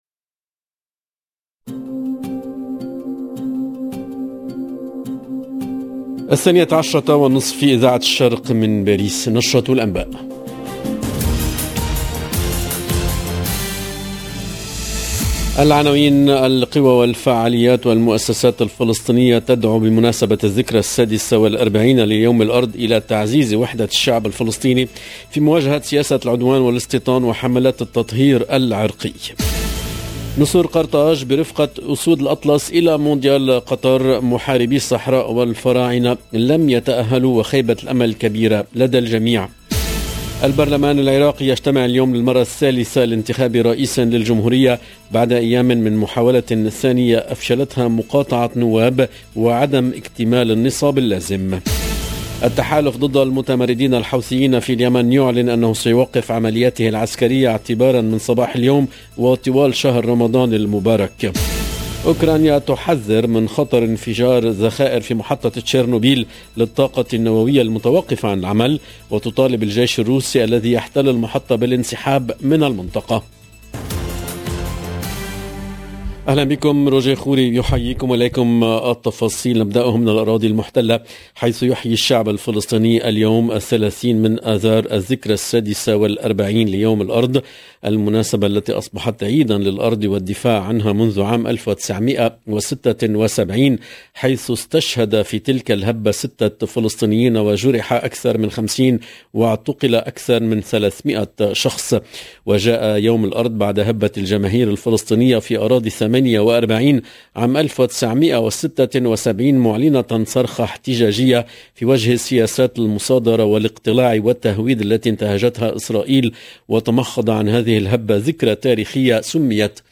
LE JOURNAL DE MIDI 30 EN LANGUE ARABE DU 30/03/22